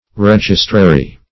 Registrary \Reg"is*tra*ry\ (- tr?*r?), n.